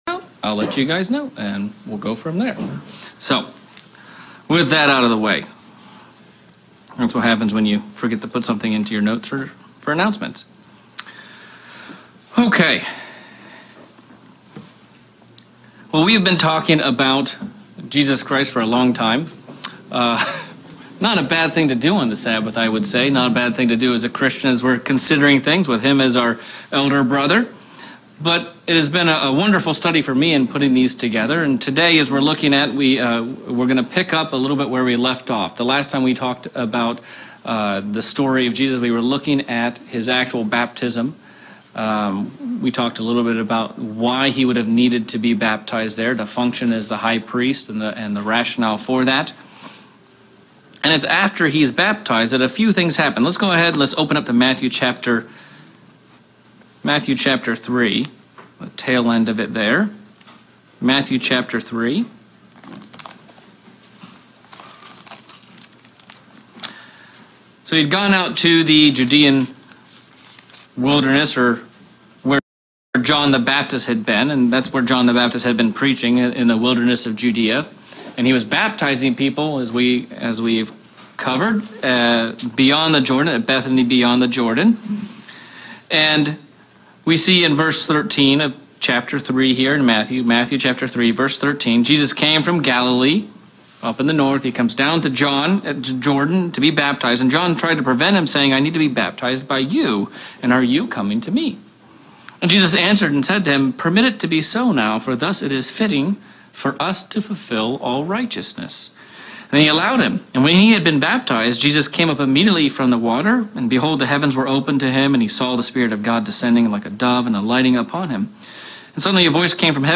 Sermon looking at the temptation of Christ in Matt 4 and Satan's reasoning behind it as well as exploring the word of scripture from where it was taken from and the context that these add to what Christ was doing and his message for us